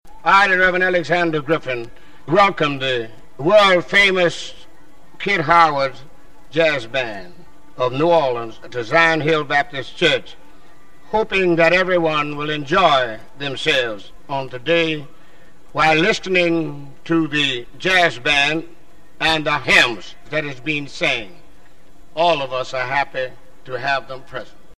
at Zion Hill Church
Welcome Address, Rev.